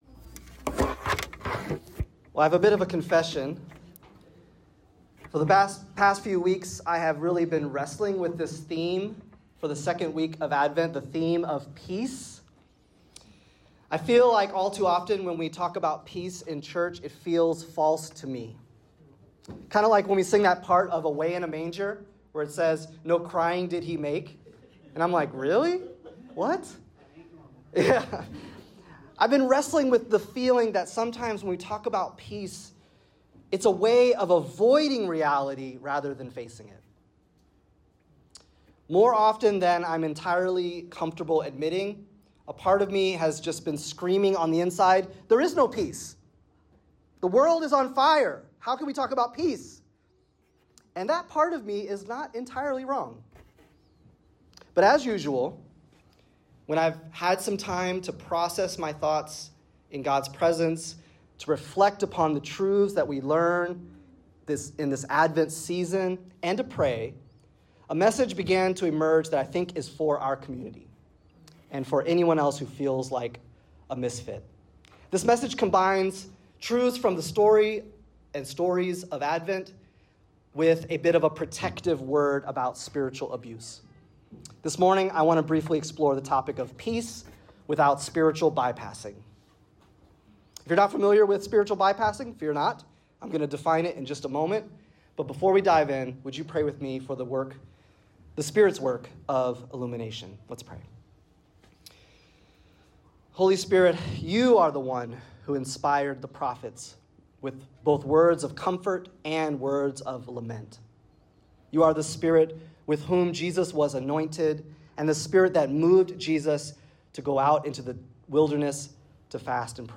This week’s Advent message wrestles with the theme of peace—not as an escape from reality but as a profound and embodied hope amid life’s struggles. The sermon critiques “spiritual bypassing,” a harmful practice where spiritual truths are used to suppress or dismiss valid emotions like grief, anger, or fear.